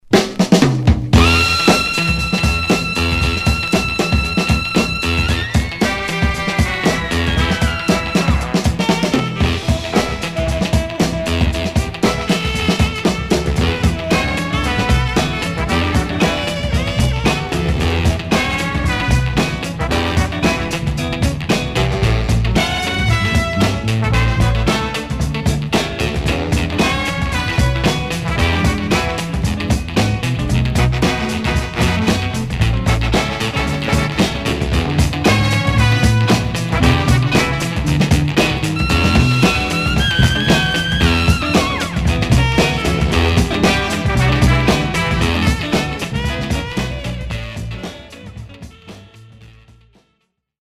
Stereo/mono Mono
Funk